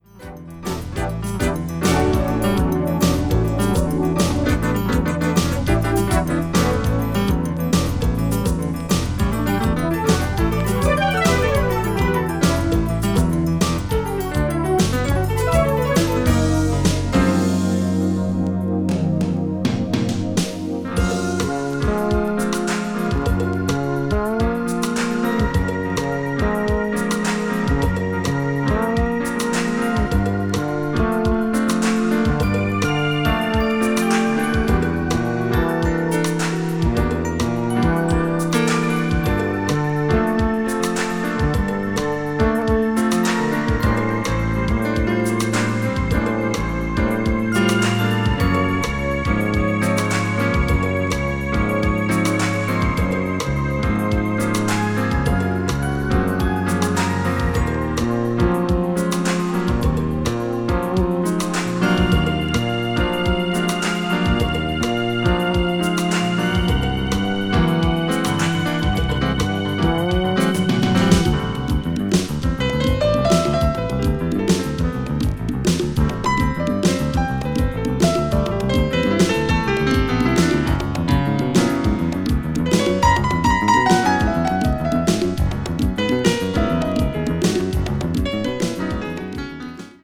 crossover   electronic   fusion   new age jazz   synthesizer